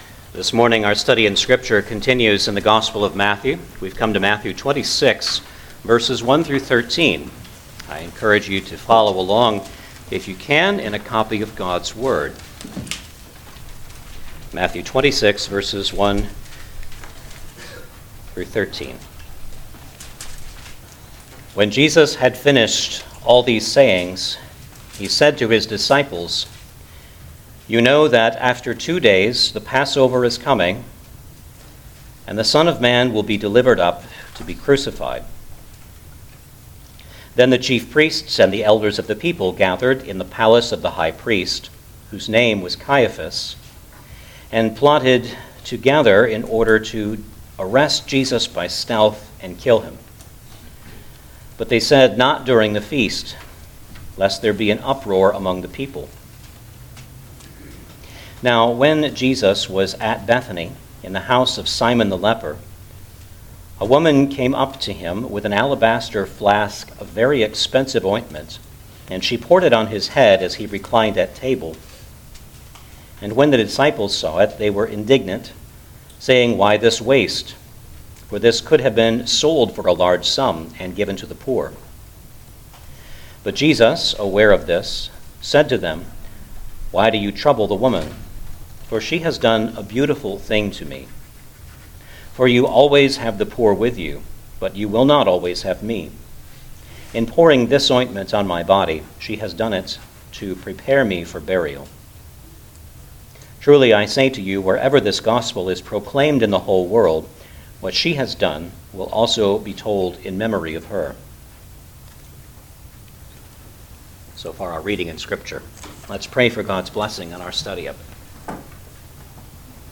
Gospel of Matthew Passage: Matthew 26:1-13 Service Type: Sunday Morning Service Download the order of worship here .